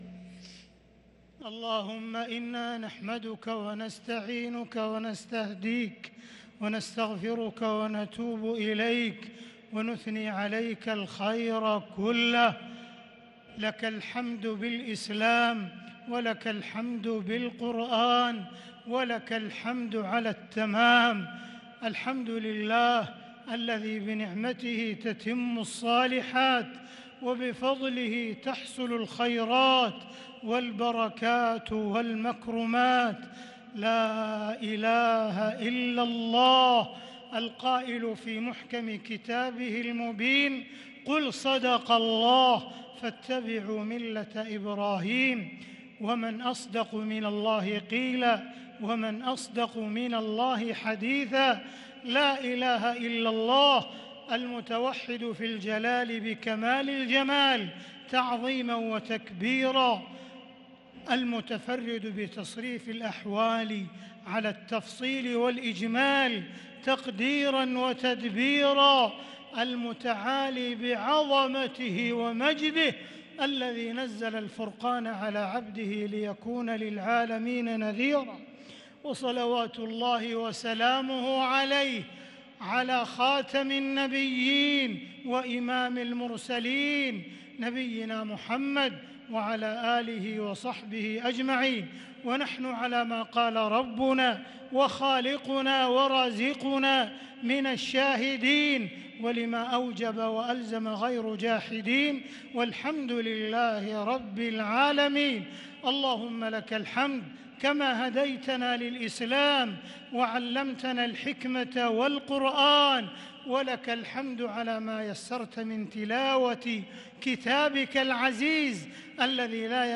دعاء ختم القرآن ليلة 29 رمضان 1443هـ | Dua for the night of 29 Ramadan 1443H > تراويح الحرم المكي عام 1443 🕋 > التراويح - تلاوات الحرمين